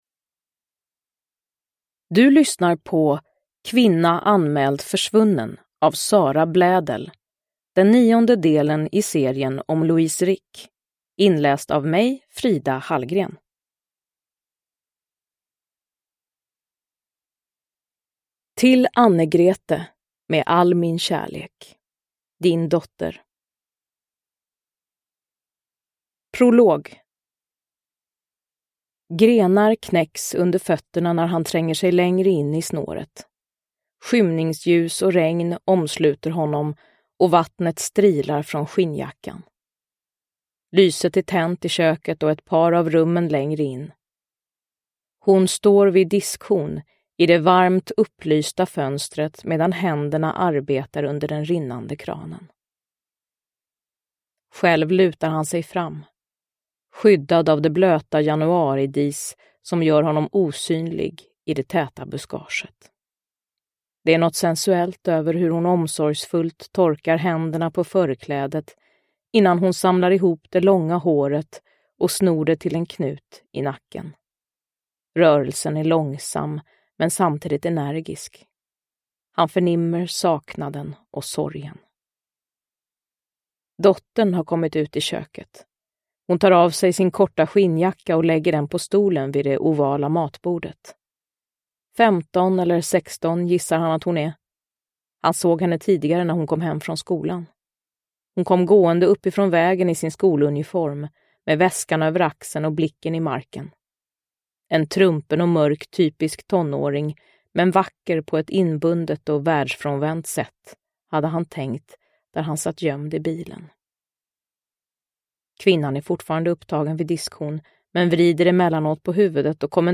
Kvinna anmäld försvunnen – Ljudbok – Laddas ner
Uppläsare: Frida Hallgren